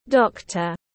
Bác sĩ tiếng anh gọi là doctor, phiên âm tiếng anh đọc là /ˈdɒk.tər/.